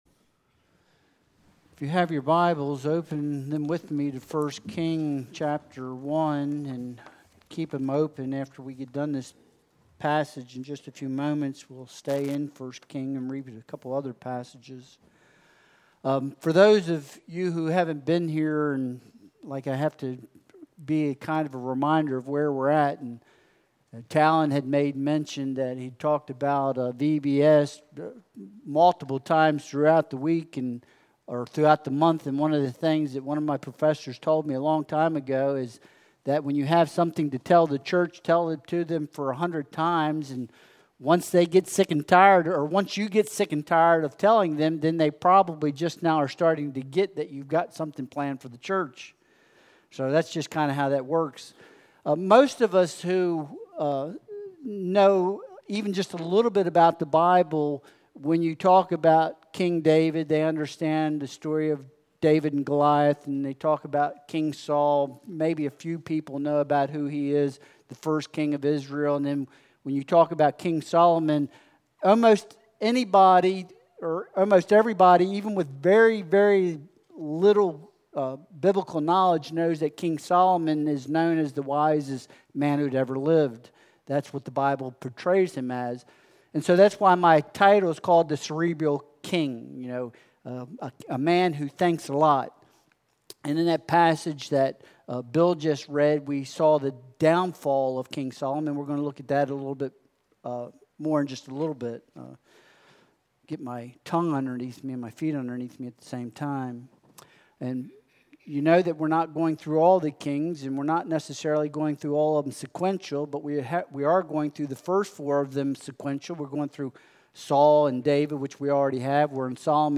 1 Kings 1.28-37 Service Type: Sunday Worship Service Download Files Bulletin « Rehoboam